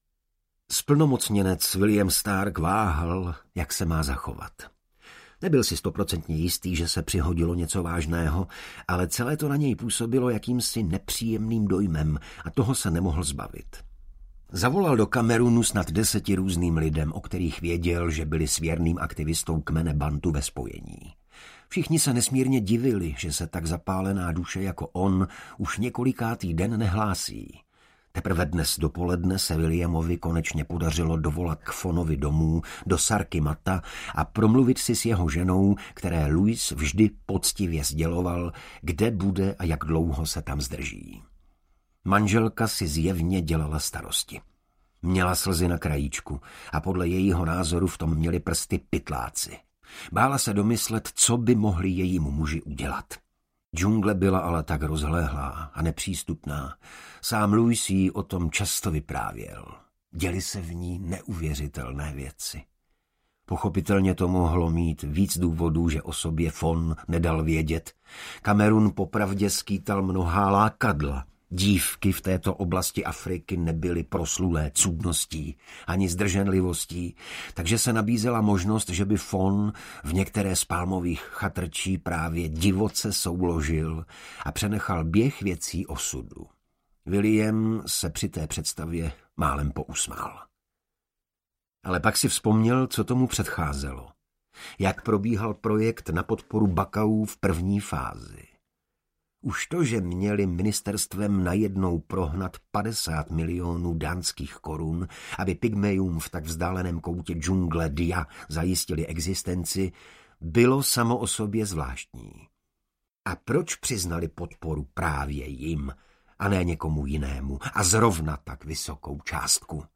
Marco audiokniha
Ukázka z knihy